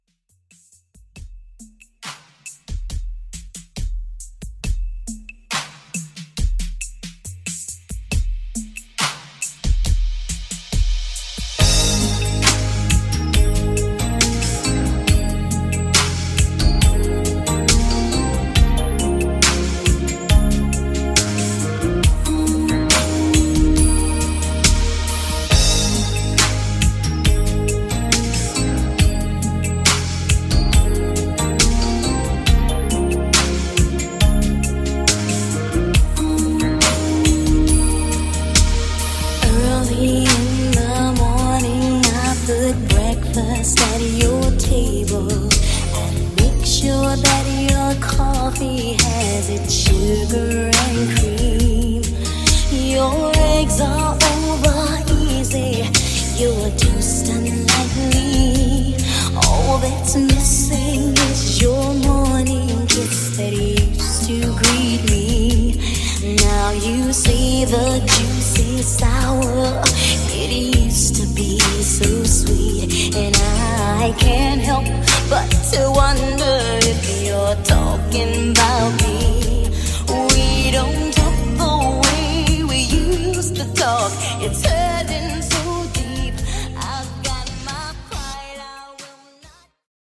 80s R&B Redrum